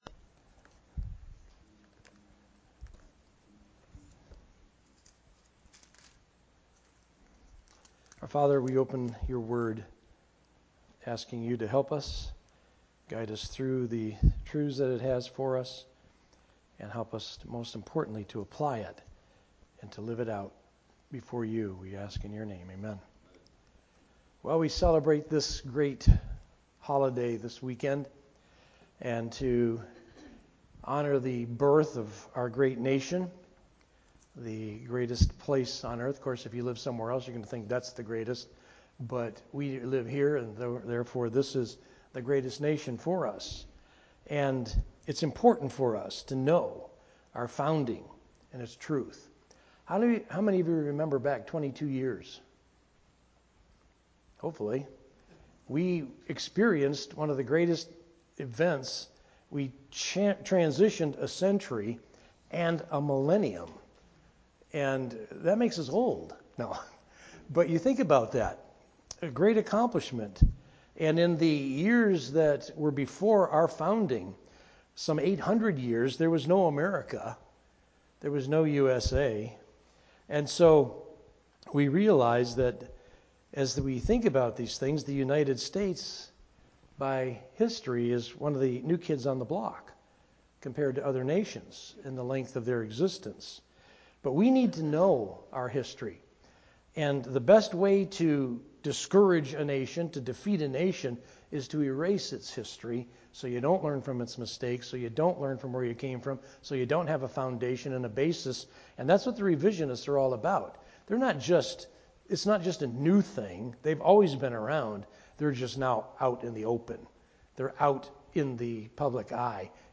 Sermon Audio | FCCNB
Related Topics: Sermon